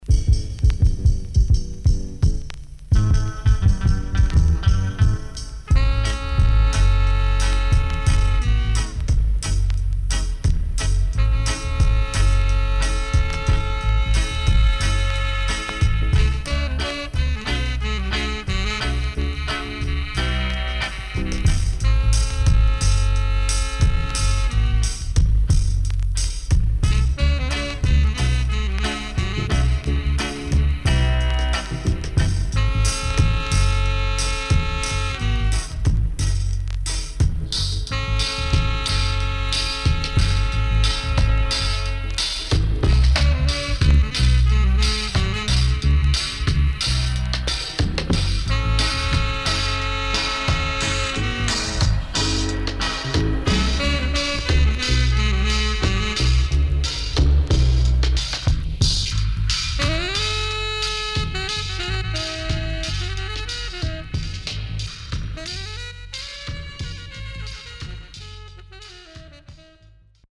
HOME > DUB
SIDE A:所々ノイズ入ります。
SIDE B:所々ノイズ入ります。